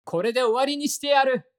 戦闘 バトル ボイス 声素材 – Battle Voice